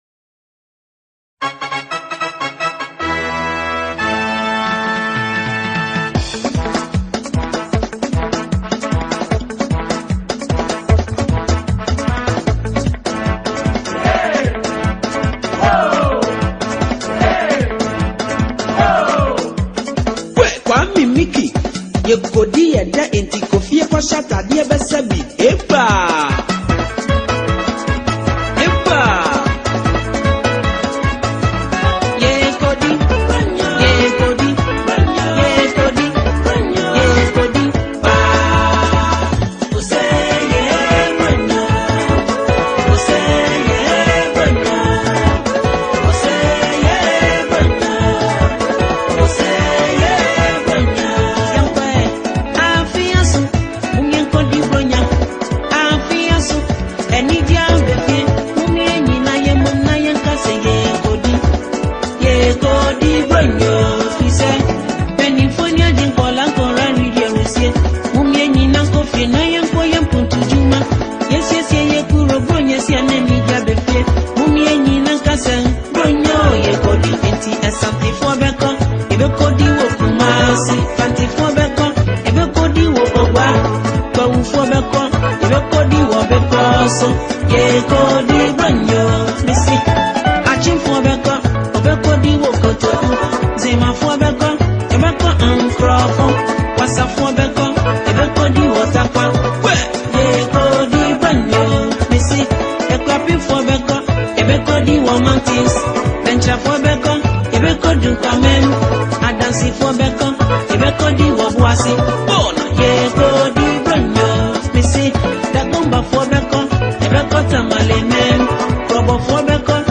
Legendary Ghanaian highlife singer/songwriter